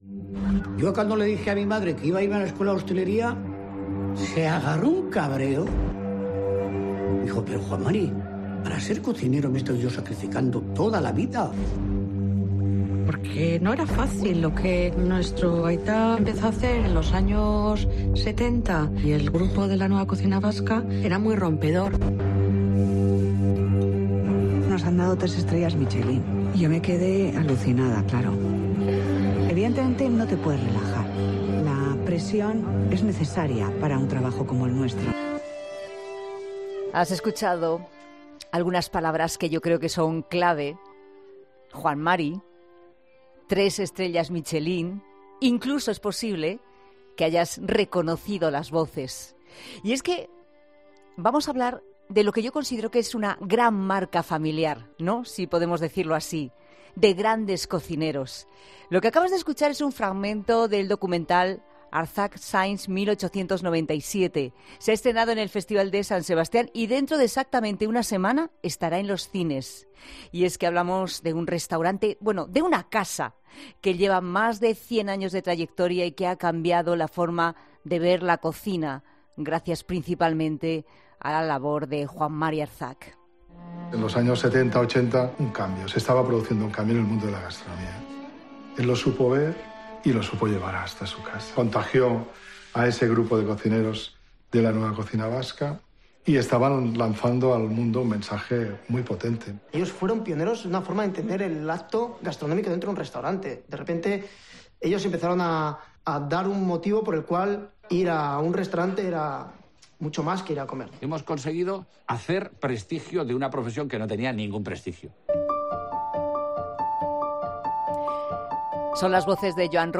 AUDIO: Ante el estreno del documental "ARZAK Since 1897", La Tarde de COPE charla con Elena Arzak.